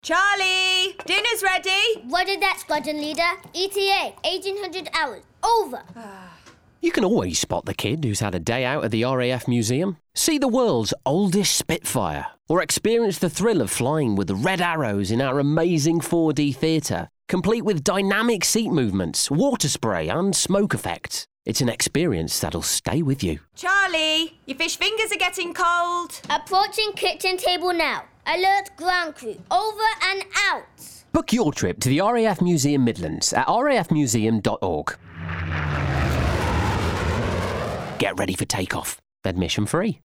The sonic ident reflected the history and longevity of the RAF – by gradually morphing the sound of a spitfire-esque plane into that of a modern fighter jet.